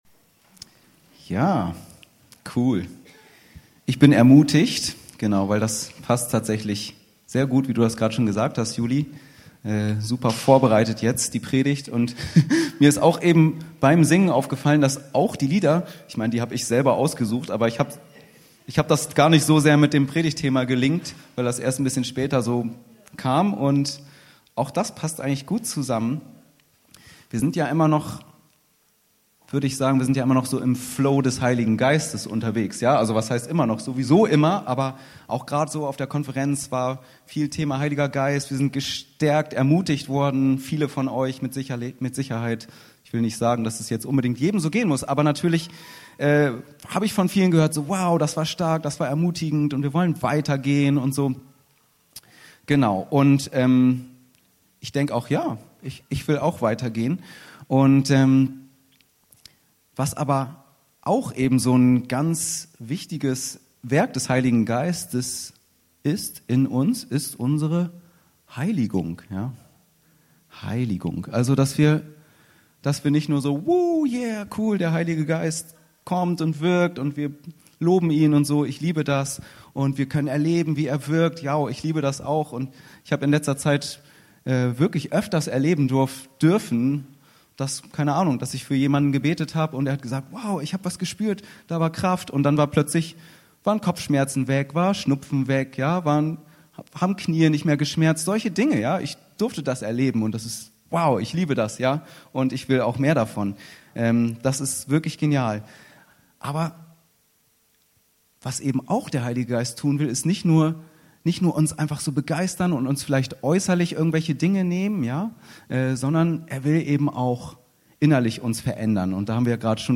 Predigten Podcast